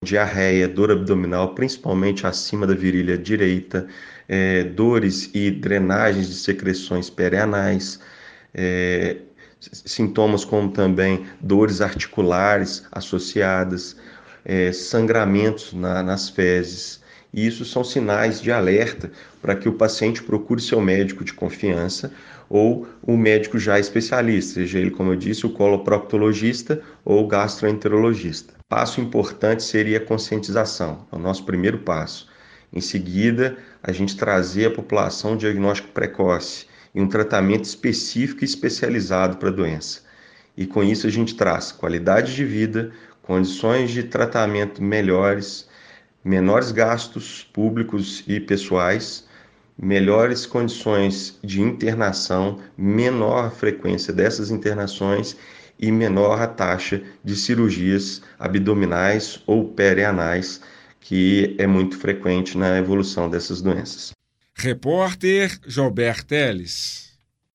Doutor, quais os sintomas as pessoas precisam observar?